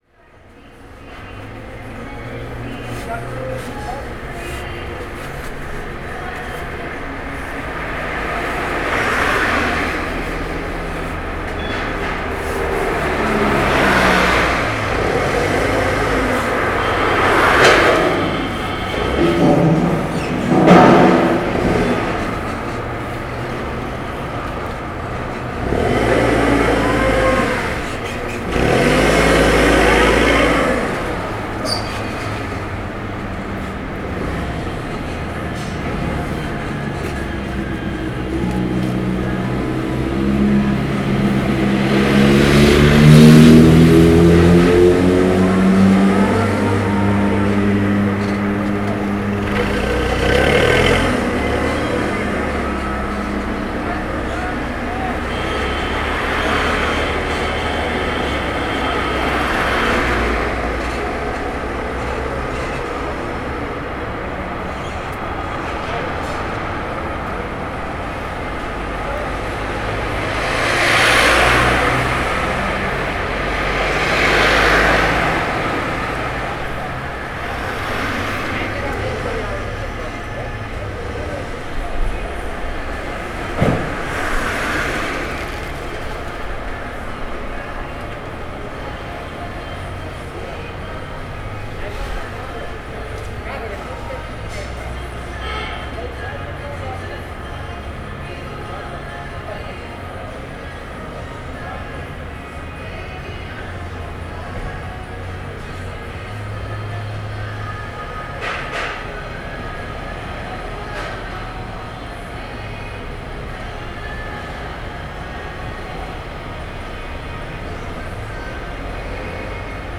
Lost Sound: traffic on the Komeetweg with sounds from small factories including a welding shop.